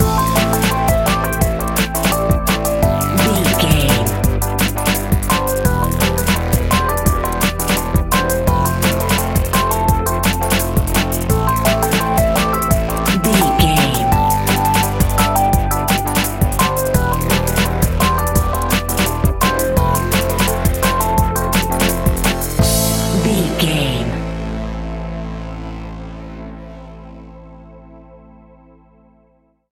Aeolian/Minor
Fast
futuristic
hypnotic
industrial
dreamy
frantic
aggressive
synthesiser
drums
electronic
sub bass
synth leads
synth bass